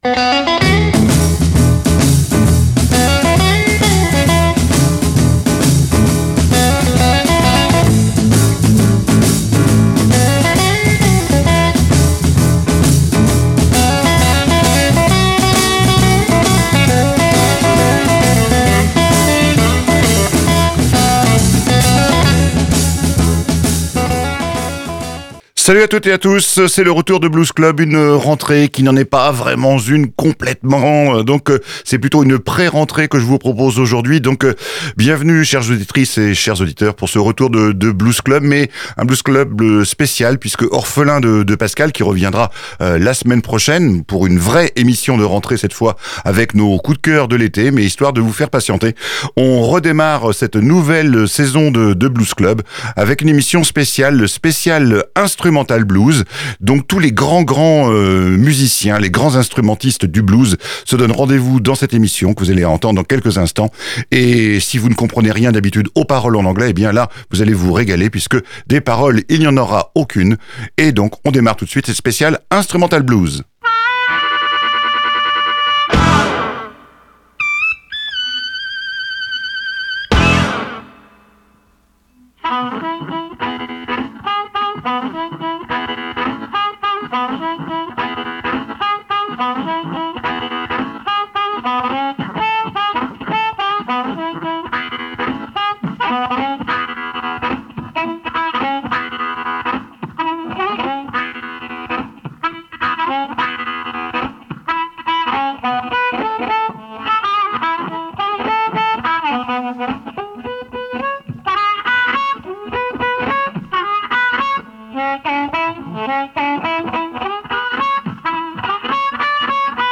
Blues Club vous propose dans cette émission 497 une spéciale « Blues instrumentaux ».
blues-club-speciale-blues-instrumentaux.mp3